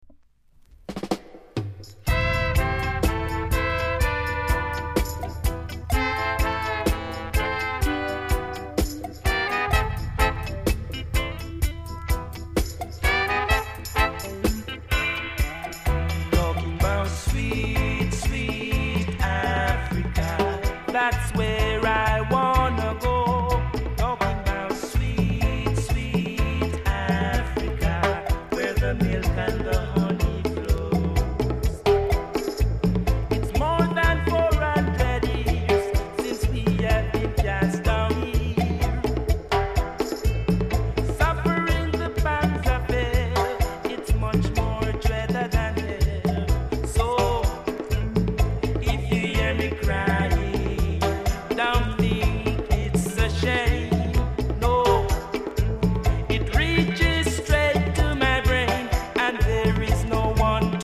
※小さなチリ、パチノイズが少しあります。
コメント ROOTS CLASSIC!!RARE JA PRESS!!※裏面クレジット間違い